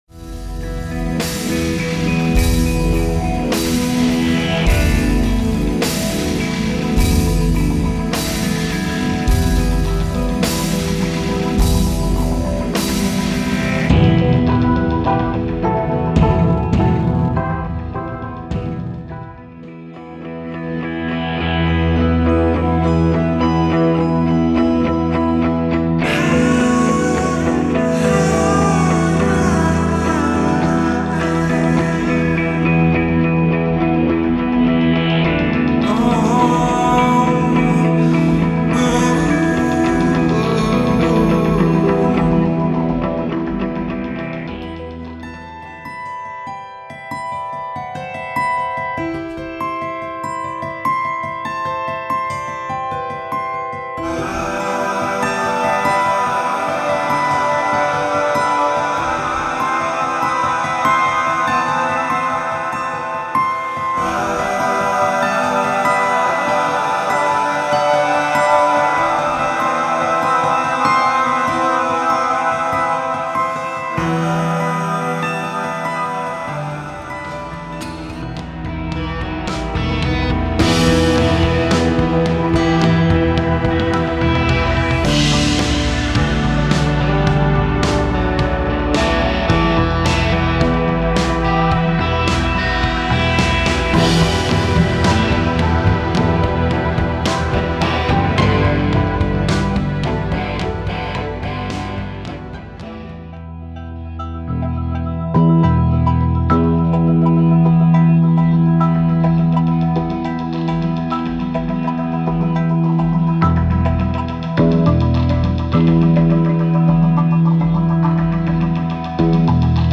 Alt.1:  A sparser, longer version of the Main Theme   1:28